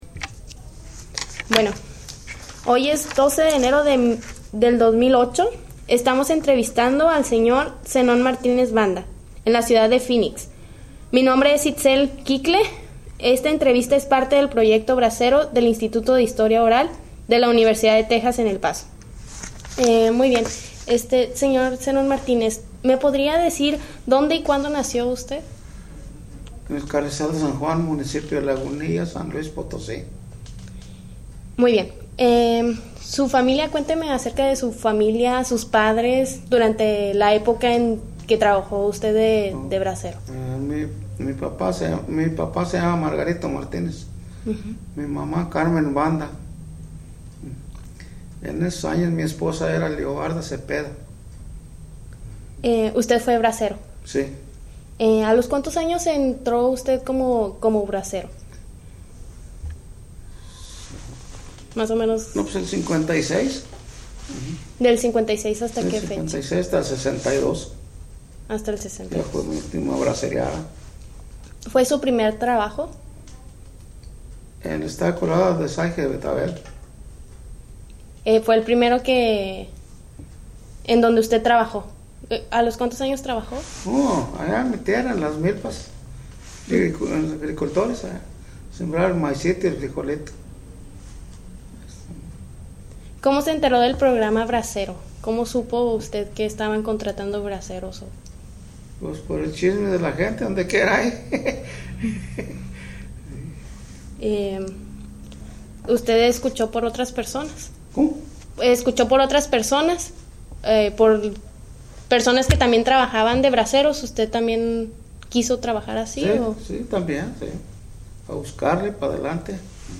Summary of Interview: